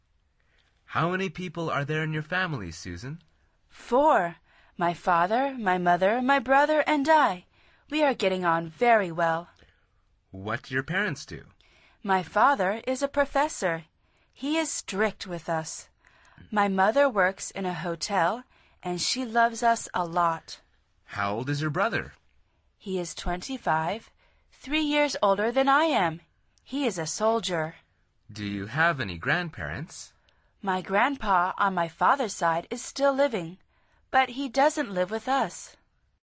Curso Intermedio de Conversación en Inglés
Al final repite el diálogo en voz alta tratando de imitar la entonación de los locutores.